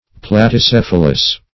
Search Result for " platycephalous" : The Collaborative International Dictionary of English v.0.48: Platycephalic \Plat`y*ce*phal"ic\, Platycephalous \Plat`y*ceph"a*lous\, a. [Platy + Gr.